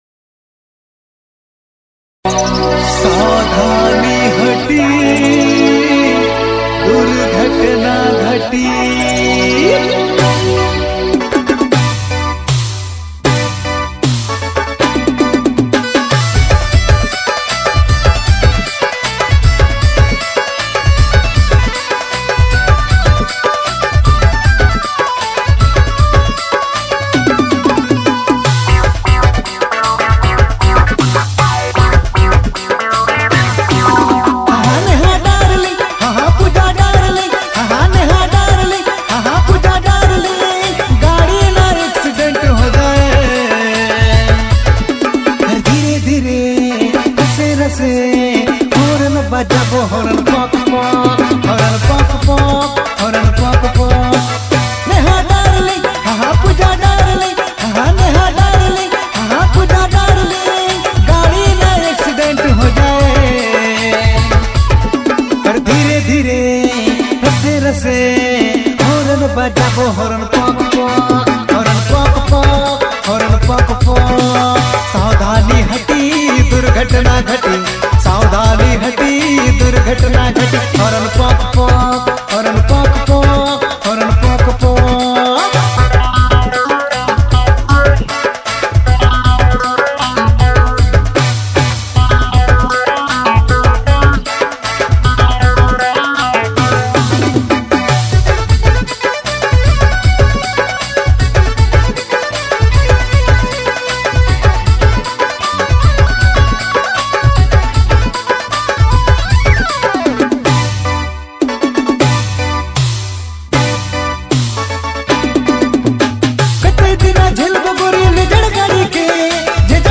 is a vibrant Nagpuri track